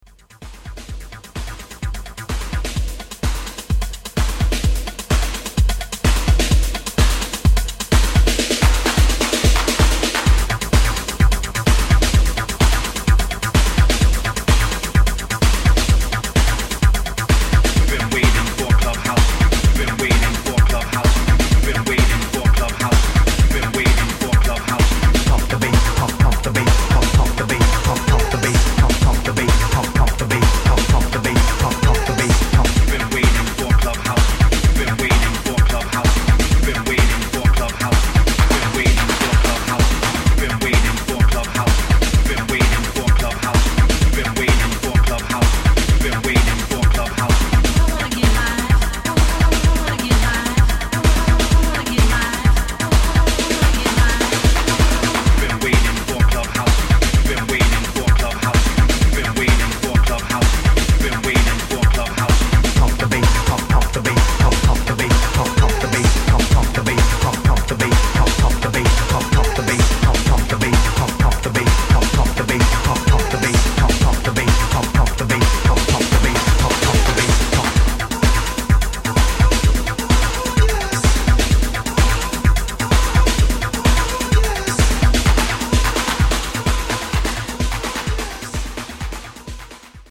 Chicago house